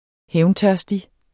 Udtale [ ˈhεwnˌtɶɐ̯sdi ]